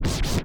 scratch09.wav